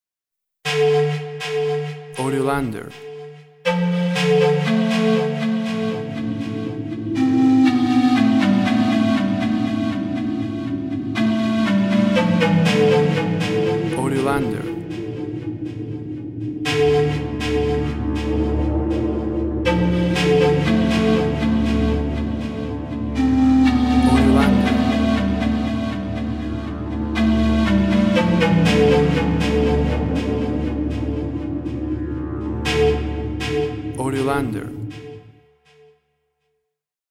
WAV Sample Rate 16-Bit Stereo, 44.1 kHz
Tempo (BPM) 60